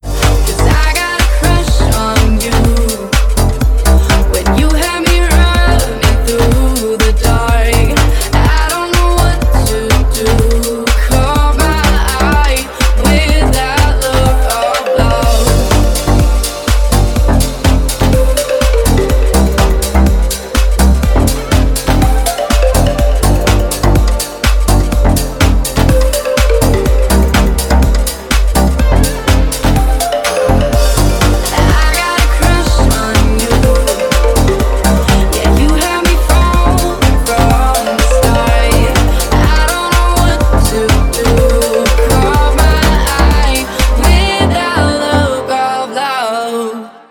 • Качество: 320, Stereo
женский вокал
dance
club